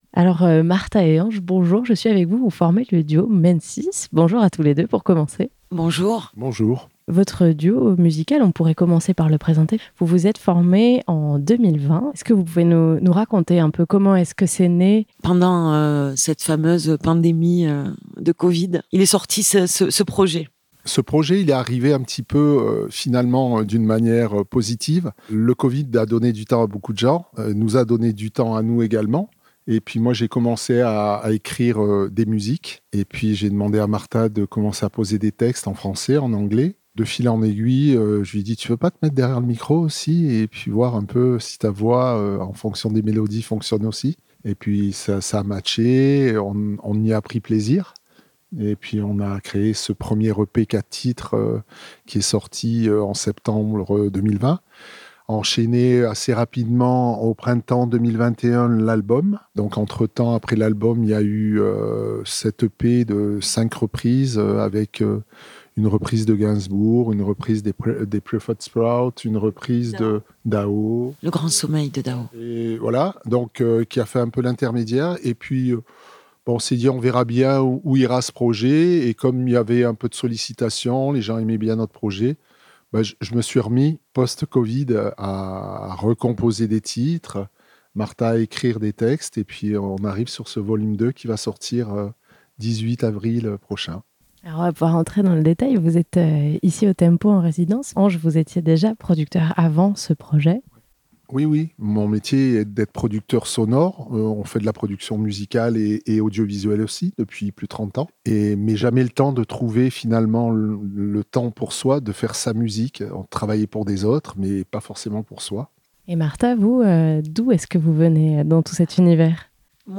L'occasion pour eux de présenter sur scène leur nouvel album, le deuxième, dans lequel ils ont voulu partager leurs expériences de vie, le chemin parcouru, leur rapport au temps et à la vanité des choses. Ils nous ont livré une interview pleine d'une belle complicité et de jo